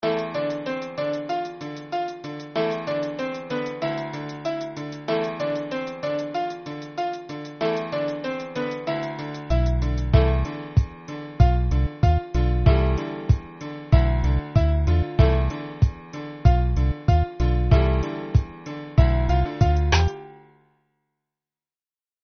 (инструментальный); темп (91); продолжительность (3:10)